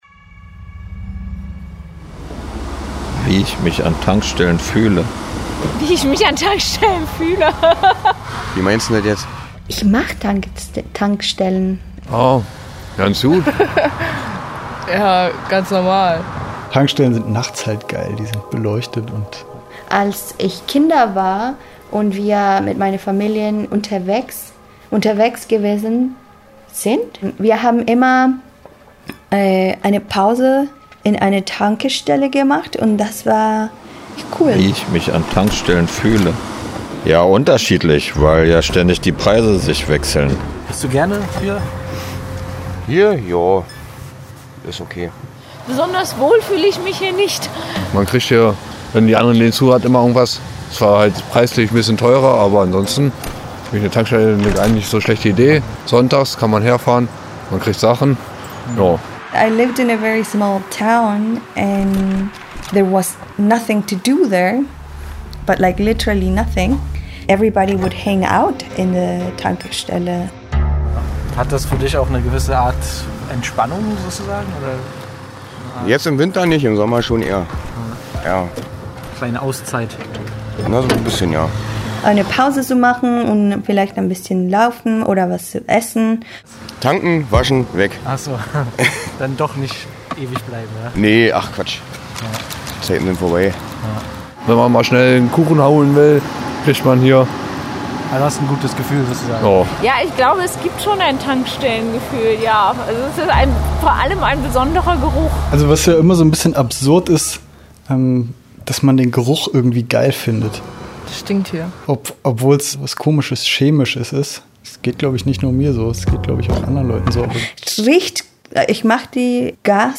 Diesen Fragen stellen sich Besucher*innen einer Tankstelle im Norden Berlins, begleitet von einer experimentellen Komposition, einem Professor und einem Roboter, der als Medium der Fossilien spricht.